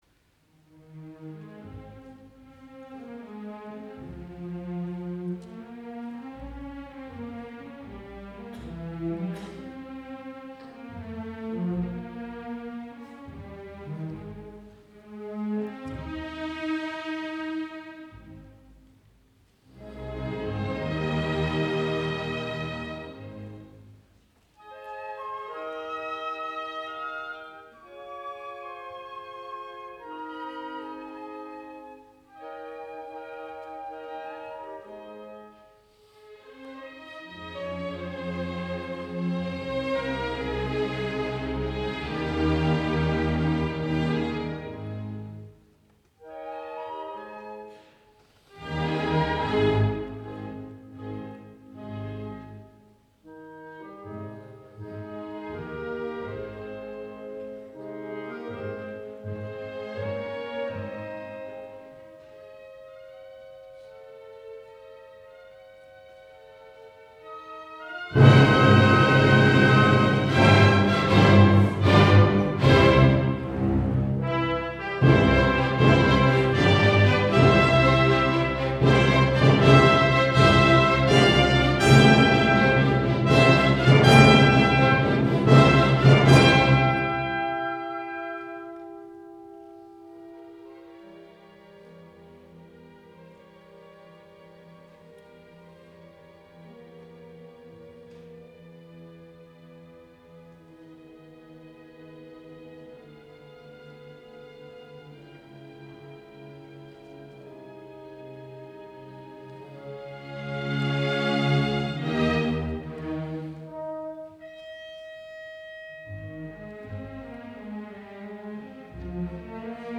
2. Andante con moto